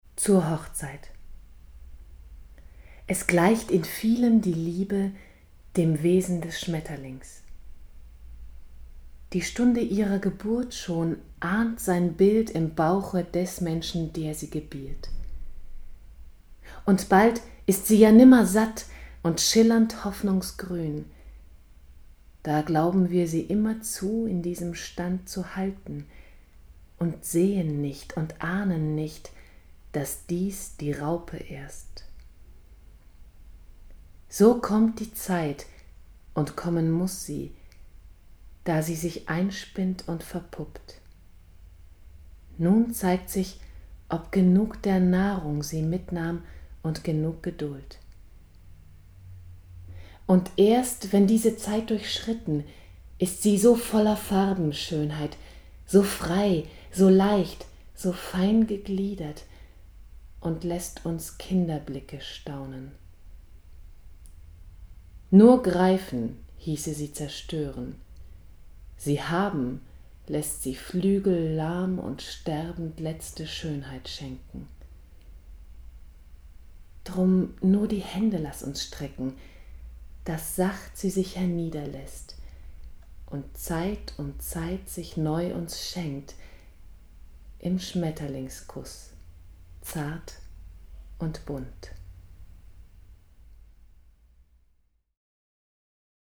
Hier findest du eine Auswahl verschiedener Stimmfarben und Sprachen, die ich bedienen kann: